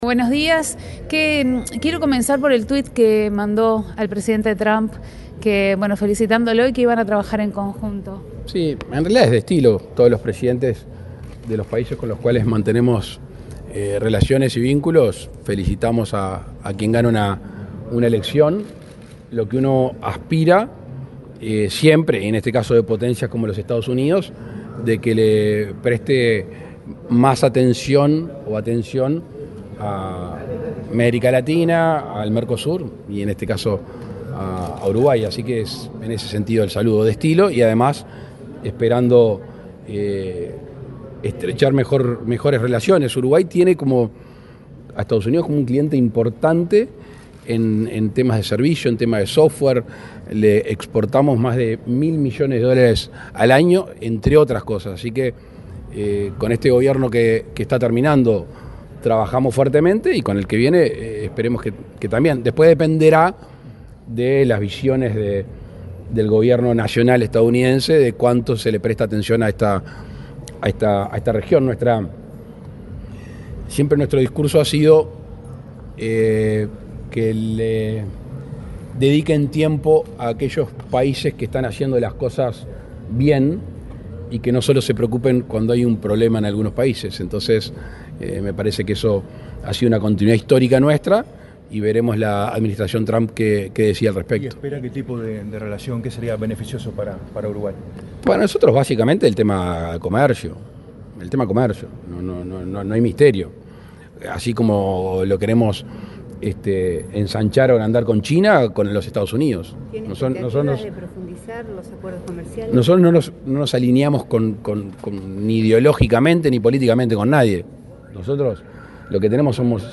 Este miércoles 6, el presidente de la República, Luis Lacalle Pou, dialogó con la prensa en Punta del Este, luego de participar en la reunión de